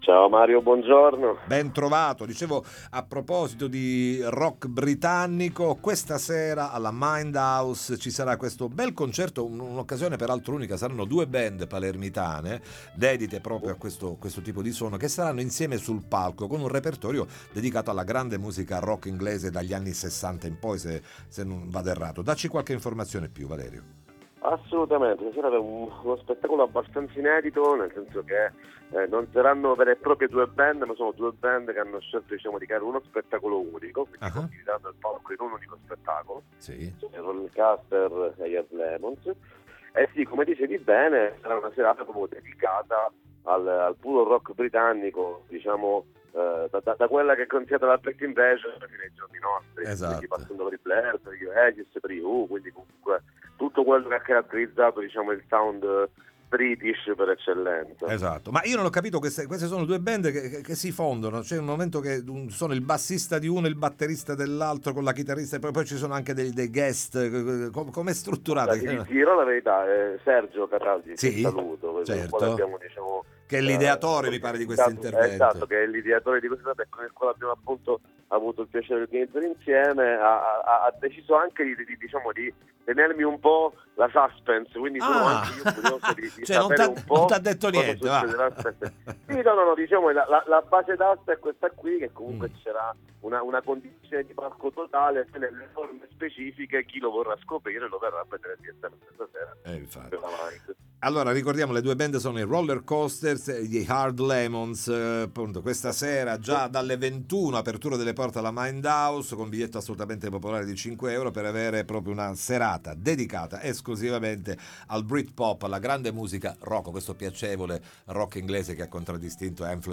Omaggio al Brit Rock Interviste Time Magazine 12/12/2025 12:00:00 AM